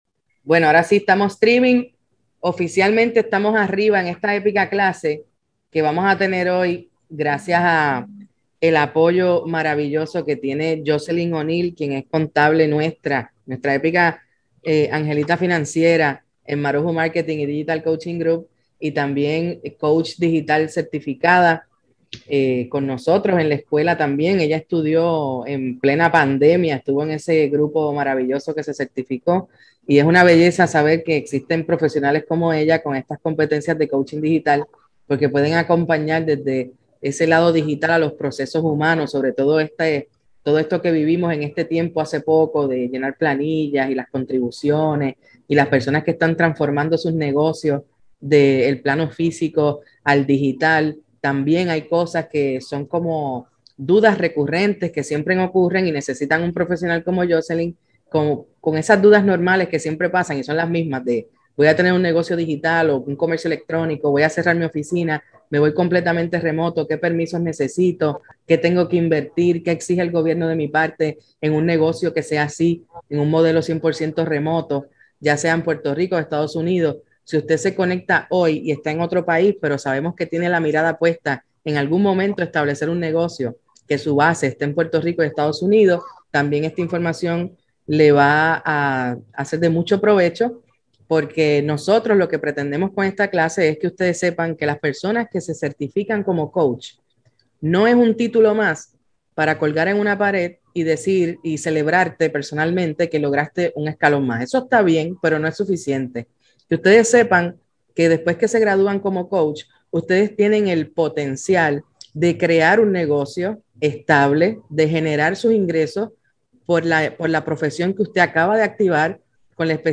Masterclass ¿Cómo comenzar tu negocio de servicios profesionales?